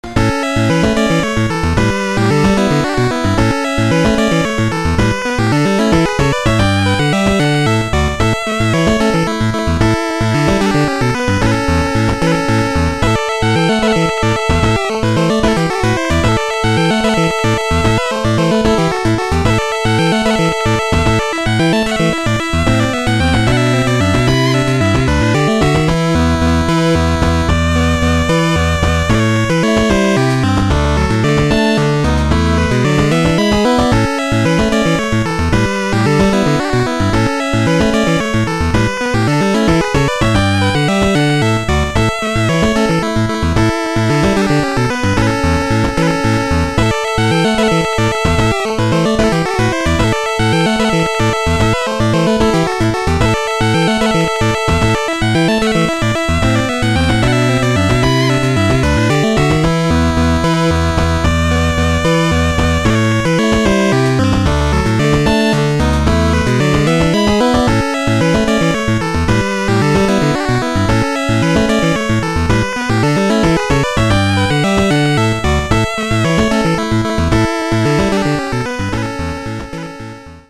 this celtic-flavored piece becomes its music.